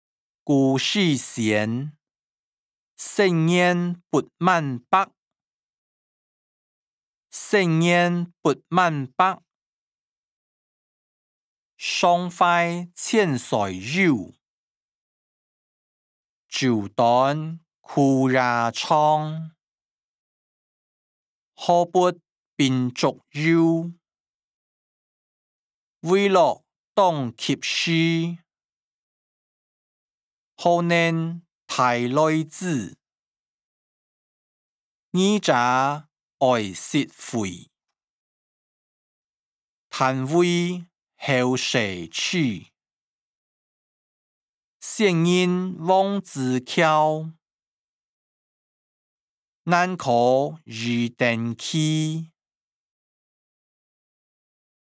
古典詩-生年不滿百音檔(海陸腔)